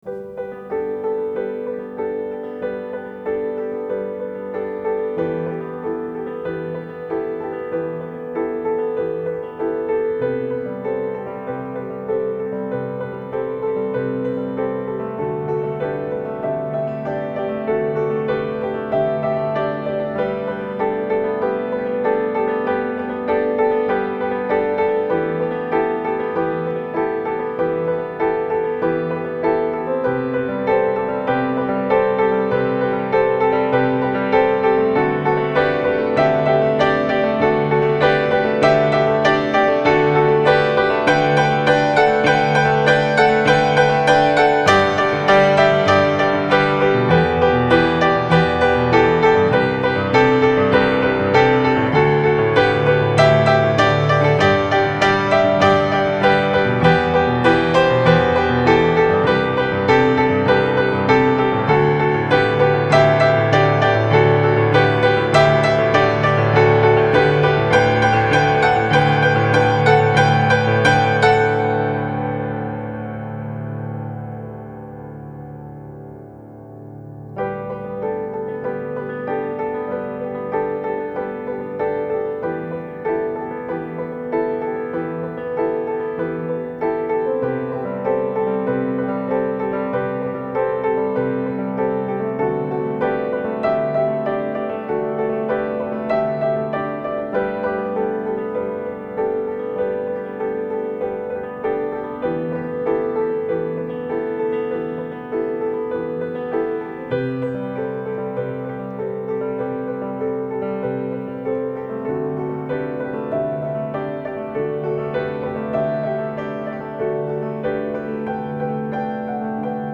Melodic Contemporary Piano